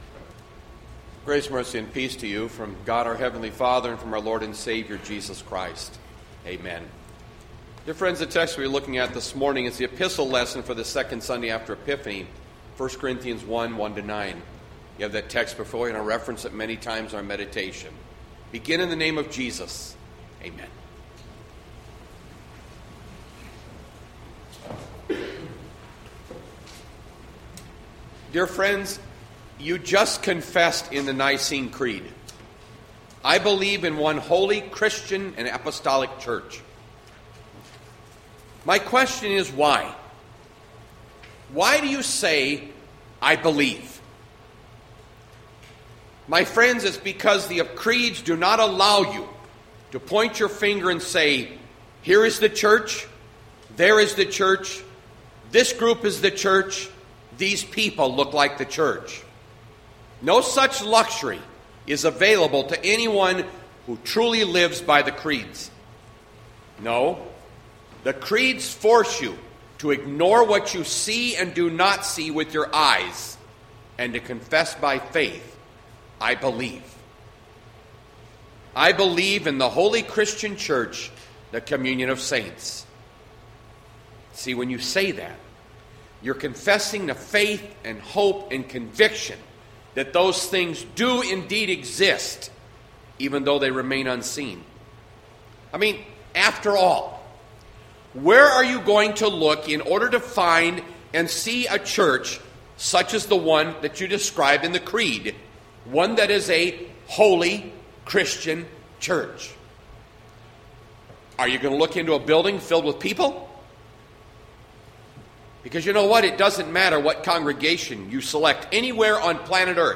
Jan 19, 2020  SERMON ARCHIVE